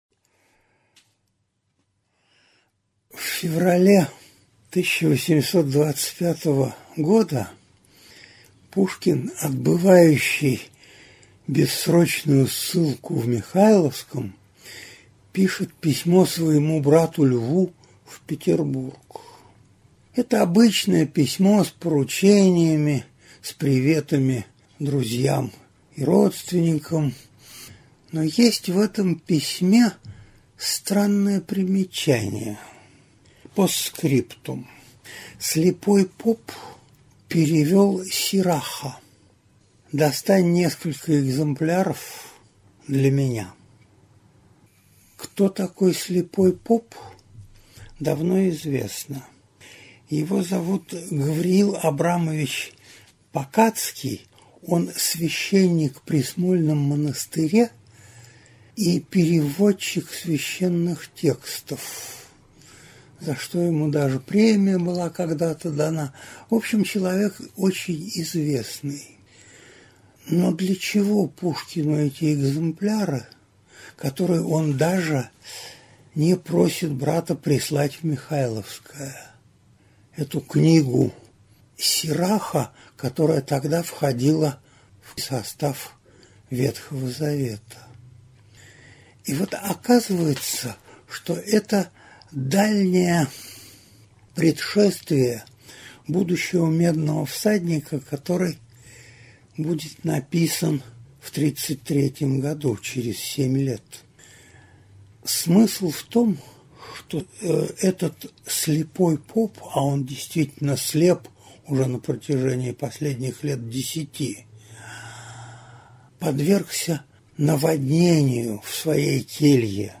Аудиокнига Поэма «Медный всадник» | Библиотека аудиокниг